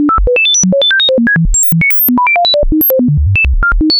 Anyways, to generate band limited noise, I figured I’d just make a sine wave that changes it’s frequency once every 4000 samples (at a sample rate of 44,100, that means it changes it’s frequency 10 times a second).
Chapter 3 Random Beeps
randombeeps.wav